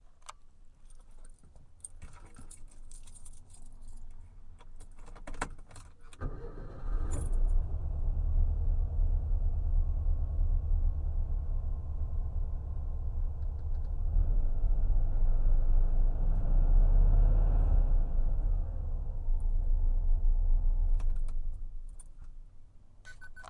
戴森DC35发动机
描述：戴森DC35发动机。
标签： 启动 VAC UUM 电动机 启动机 启动 DC35 戴森 压缩机 滤清器 发动机
声道立体声